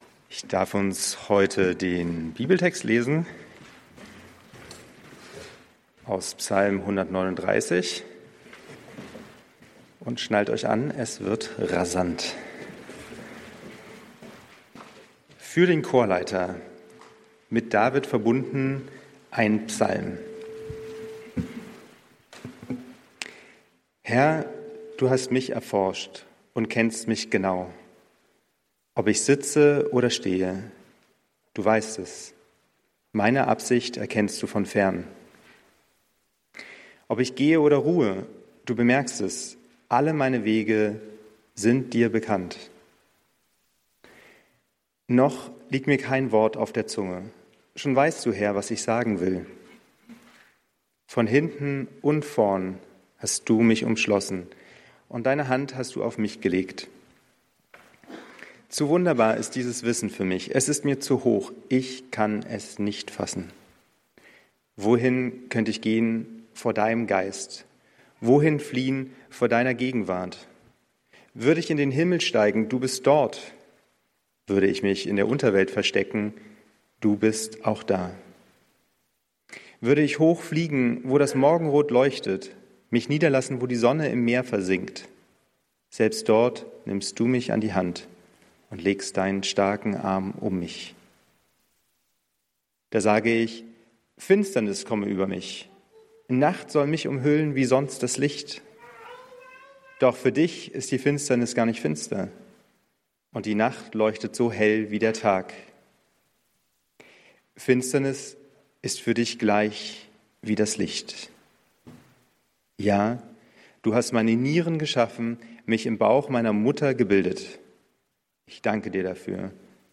Worauf wir im neuen Jahr zählen können ~ Berlinprojekt Predigten Podcast